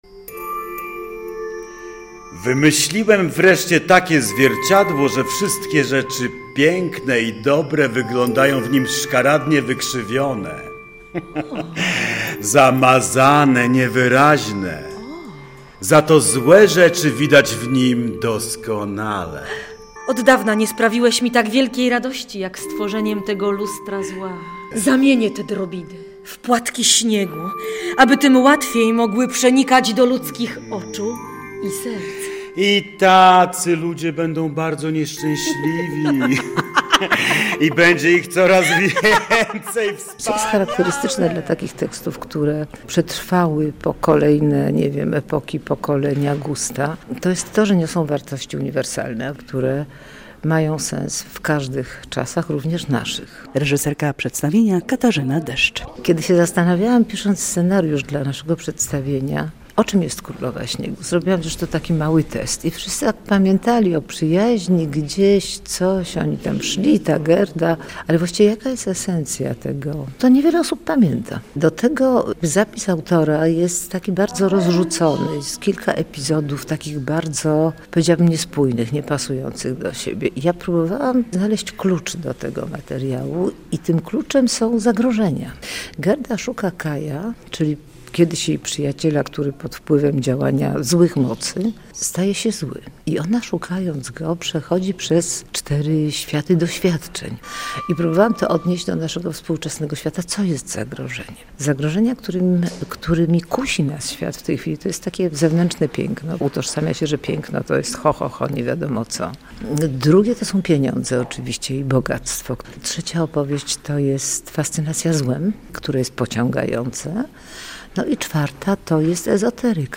Premiera „Królowej Śniegu” Teatru Dramatycznego - relacja